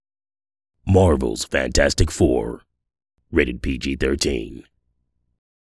Anglais (sud-africain) voice actor